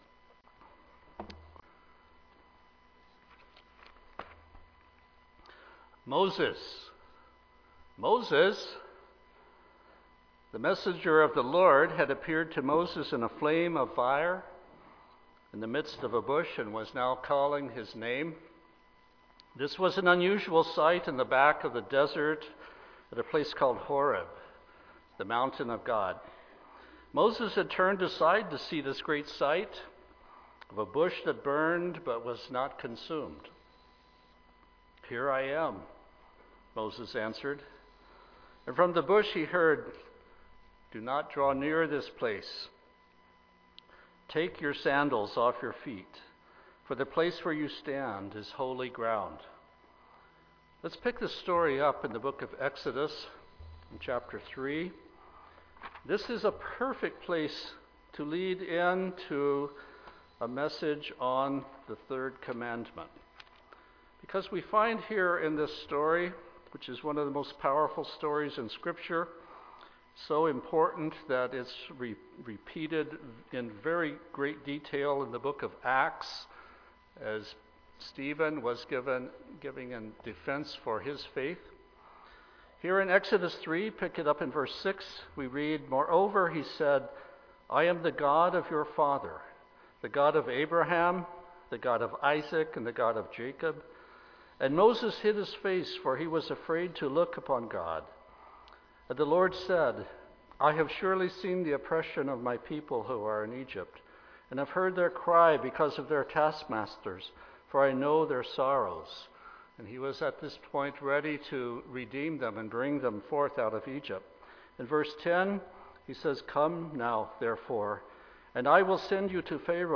Given in Tacoma, WA